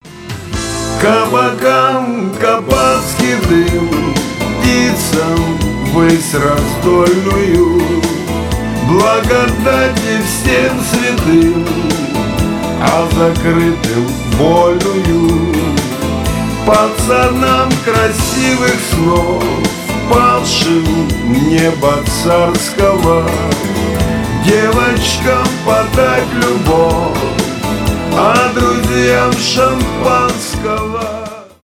шансон
душевные